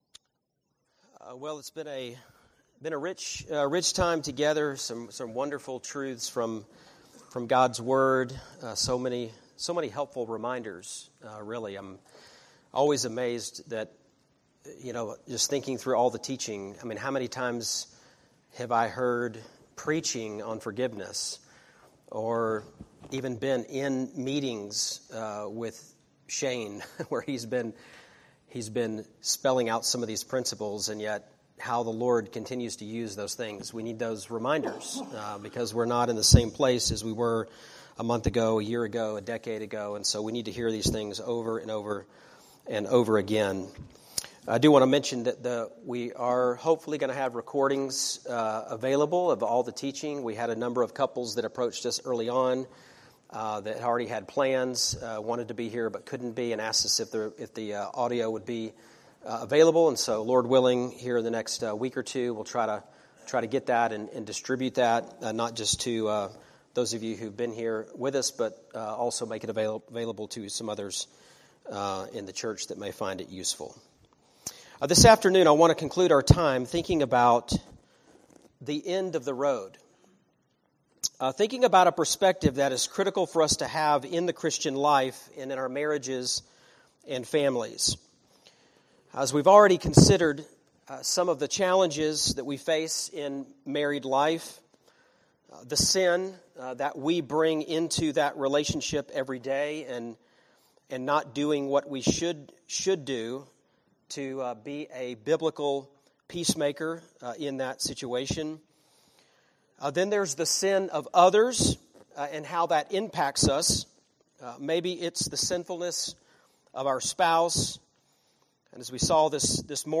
Series: Refresh - Marriage Conference